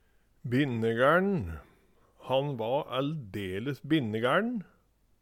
Høyr på uttala Ordklasse: Adjektiv Attende til søk